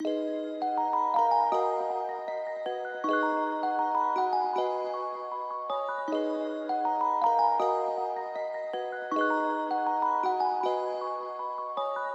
Tag: 158 bpm Hip Hop Loops Piano Loops 2.04 MB wav Key : D FL Studio